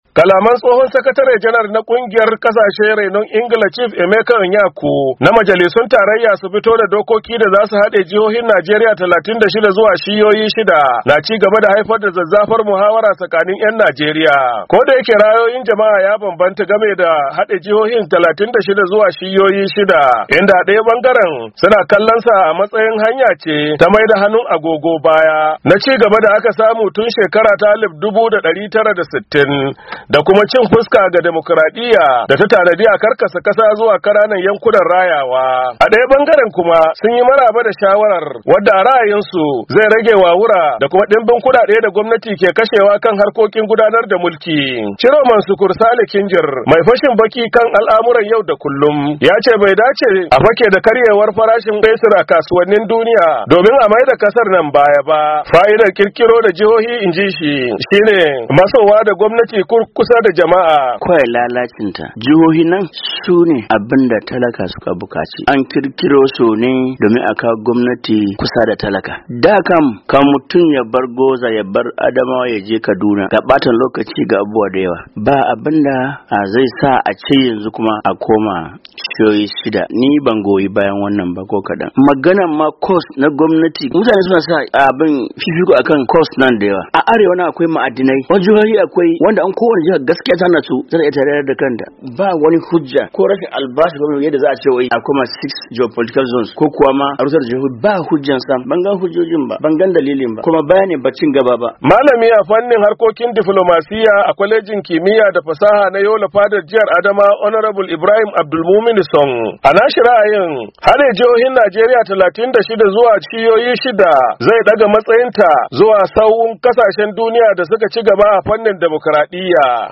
wanda ya tattaro mana ra’ayoyin wasu ‘yan Najeriya a arewa maso gabashin kasar na dauke da sauran rahoton.